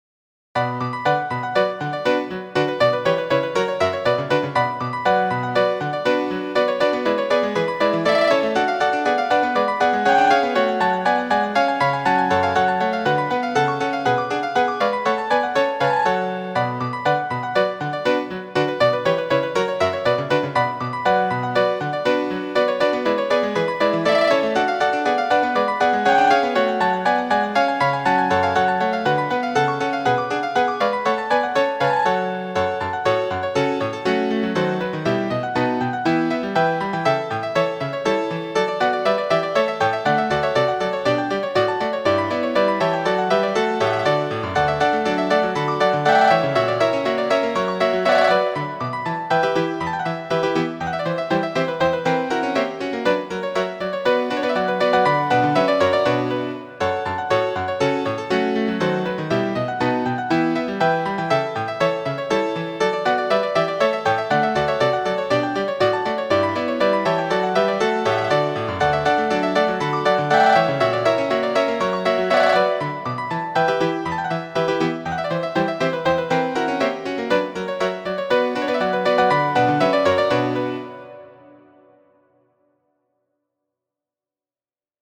Muziko:
Badinerie, 7ª peco de la orĥestra svito de J. S. Bach (badinerie en la franca signifas "sengravaĵo".